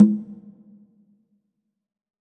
WCONGA LW.wav